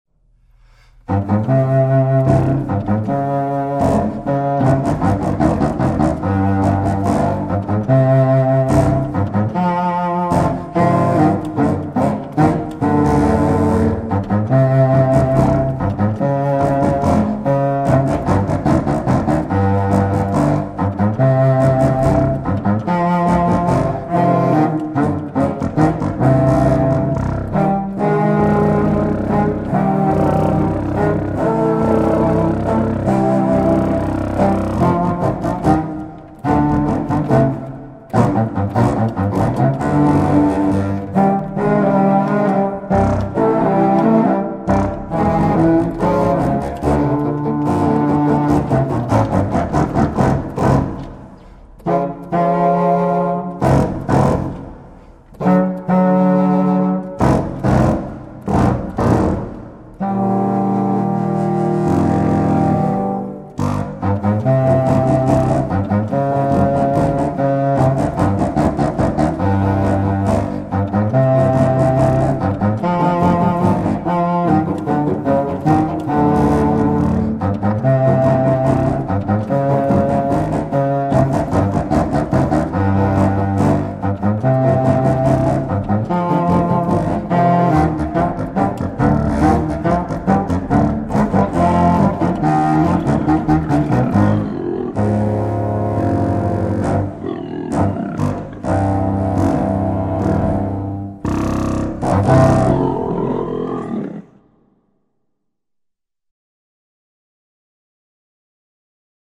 conjunto de contrafagotes